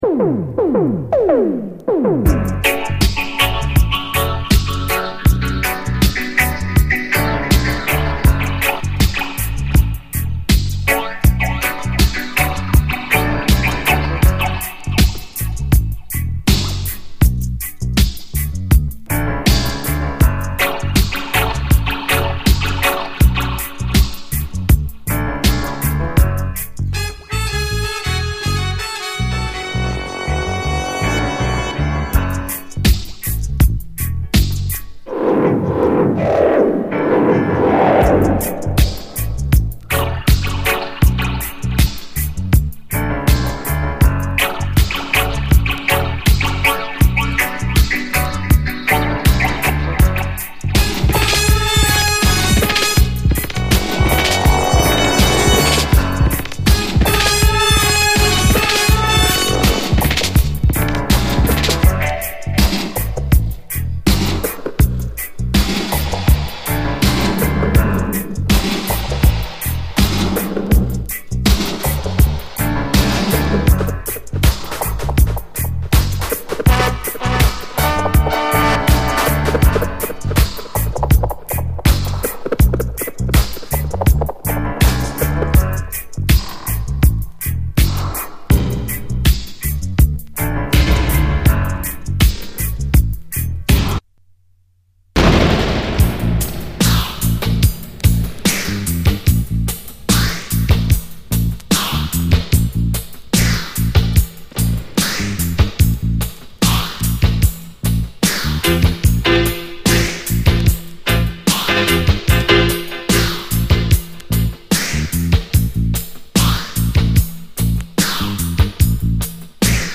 ビターなディスコ・レゲエ・チューン